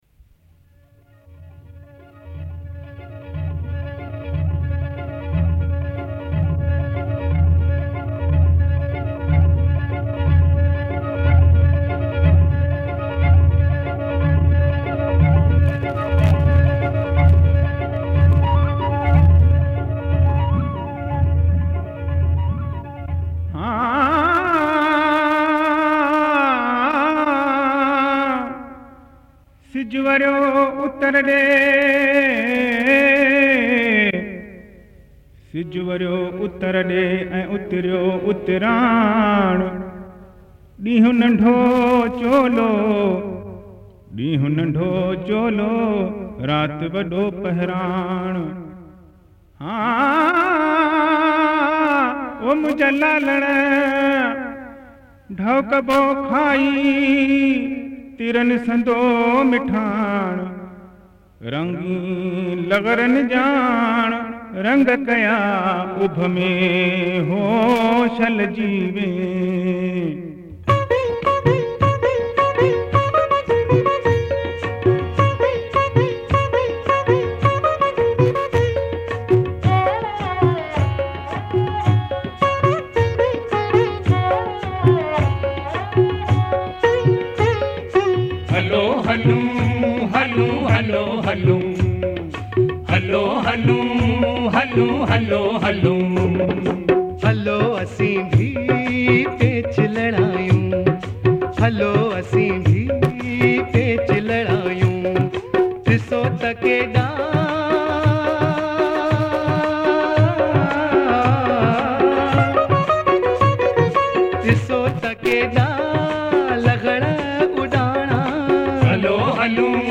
Sindhi Festival Songs